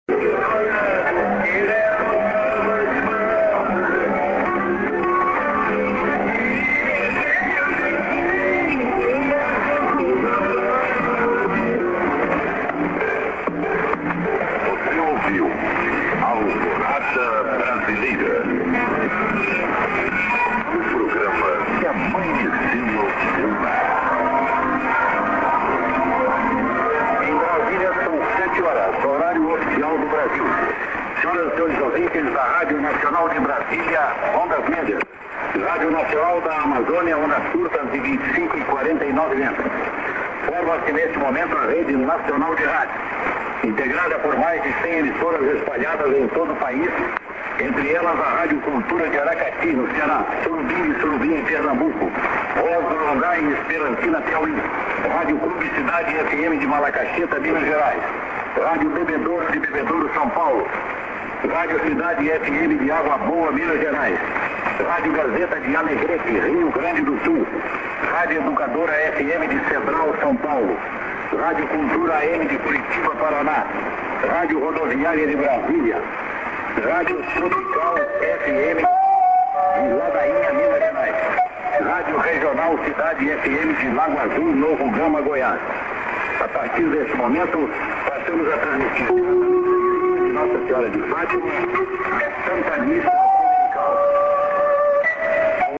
ID(men)->01':WSHB St.　＊複数の局名をアナウンスしています。